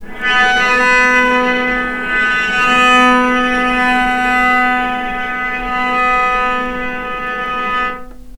healing-soundscapes/Sound Banks/HSS_OP_Pack/Strings/cello/sul-ponticello/vc_sp-B3-mf.AIF at bf8b0d83acd083cad68aa8590bc4568aa0baec05
vc_sp-B3-mf.AIF